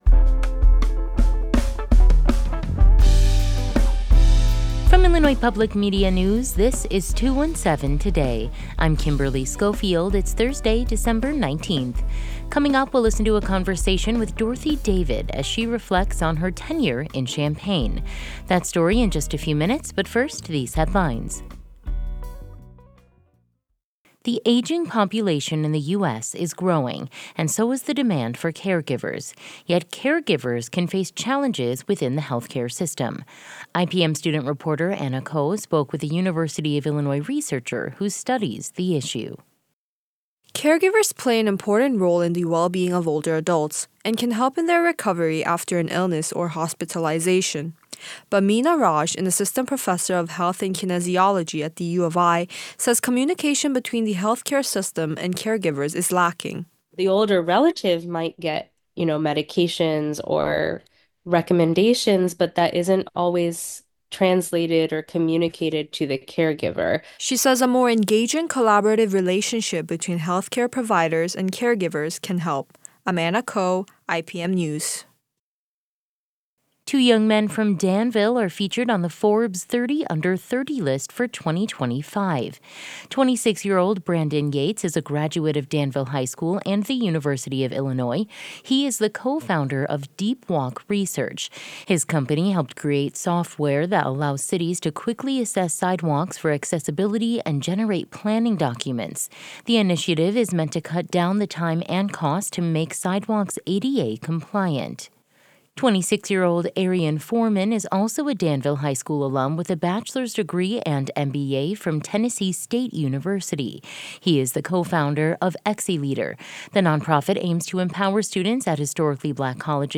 In today's deep dive, we'll listen to a conversation with Dorothy David as she reflects on her tenure in Champaign.